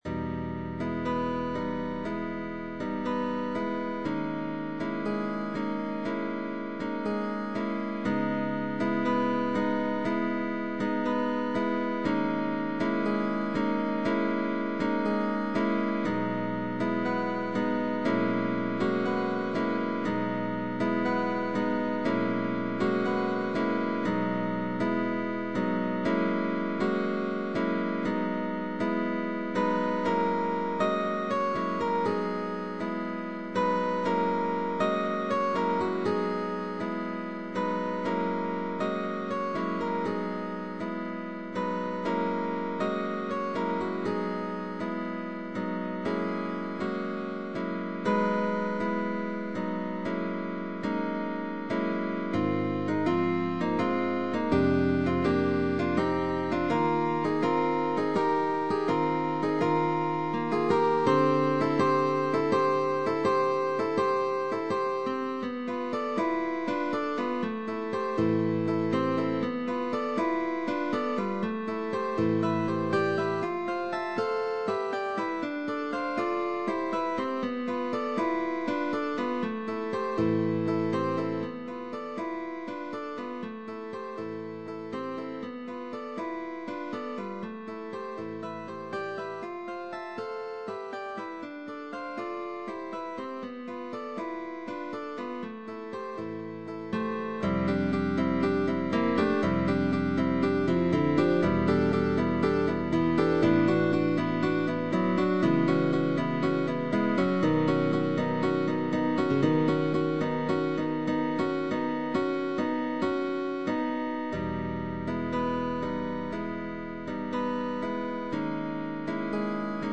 GUITAR TRIO Concert repertoire: